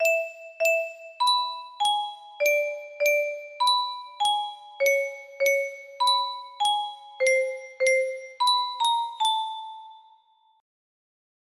Unknown Artist - Untitled music box melody
BPM 75